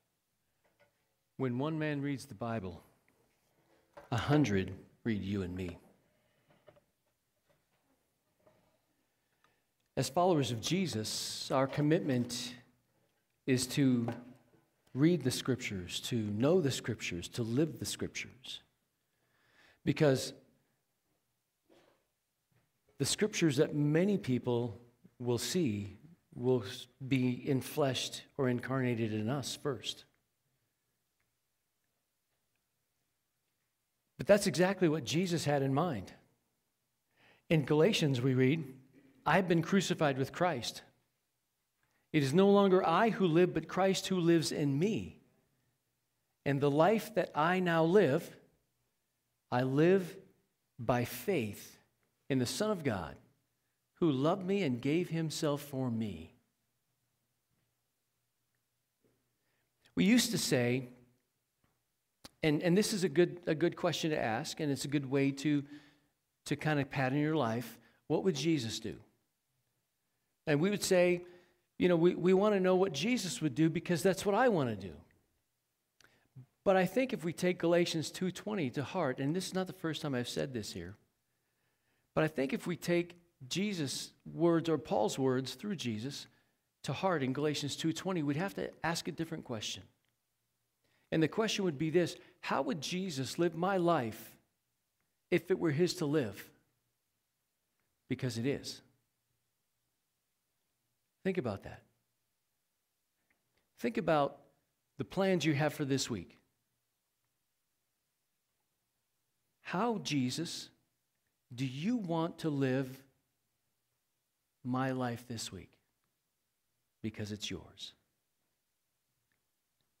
Sermons | First Baptist Church of Golden